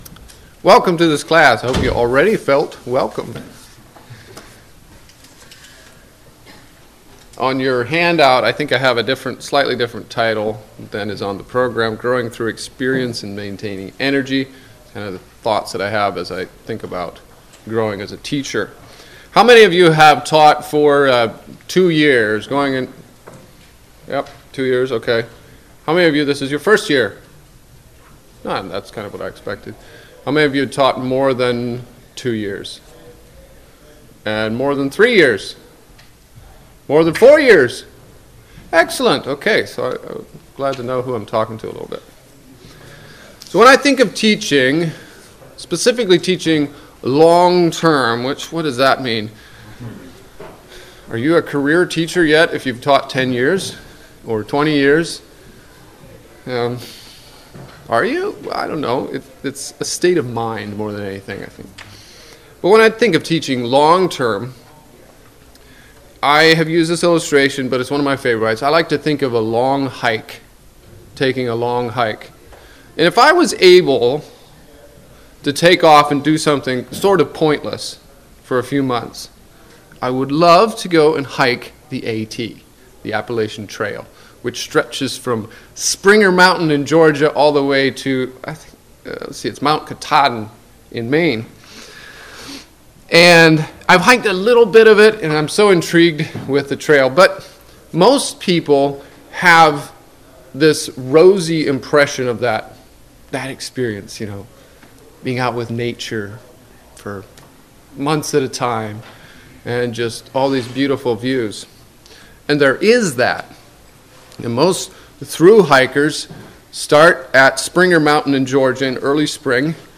Midwest Teachers Week 2025 Recordings